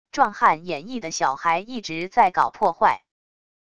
壮汉演绎的小孩一直在搞破坏wav音频